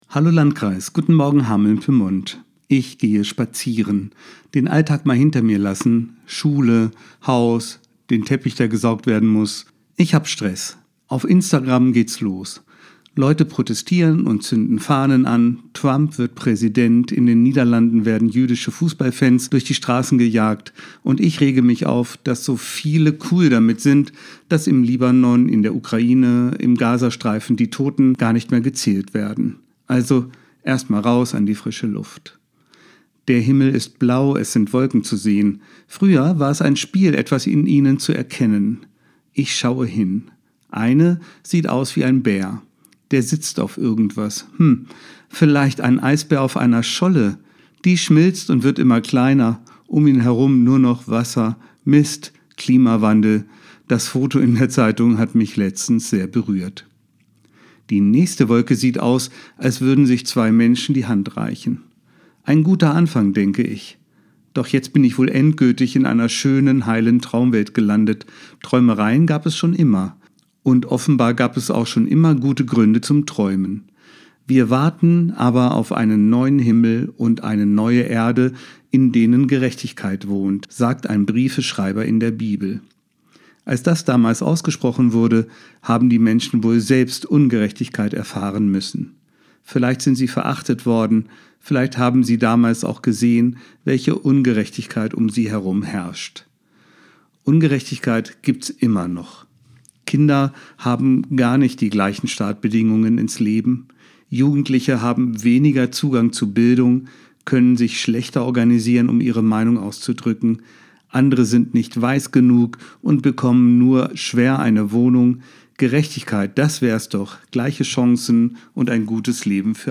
Radioandacht vom 28. November